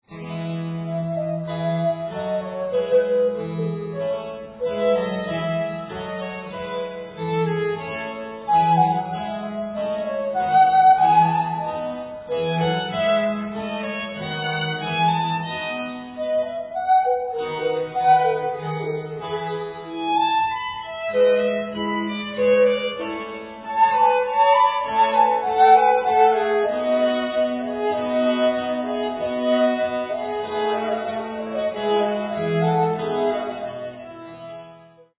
Sonata for 2 violins (or flutes) & continuo in D minor